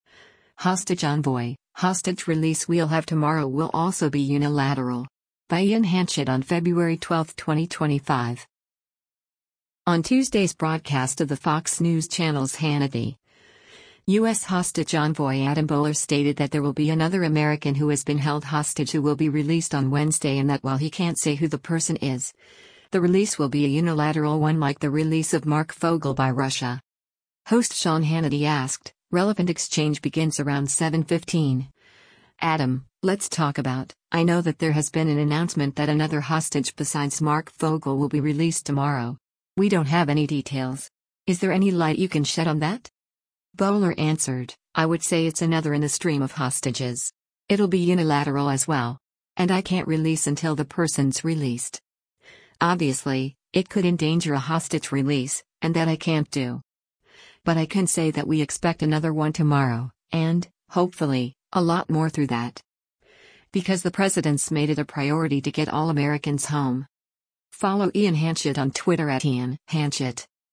On Tuesday’s broadcast of the Fox News Channel’s “Hannity,” U.S. Hostage Envoy Adam Boehler stated that there will be another American who has been held hostage who will be released on Wednesday and that while he can’t say who the person is, the release will be a “unilateral” one like the release of Marc Fogel by Russia.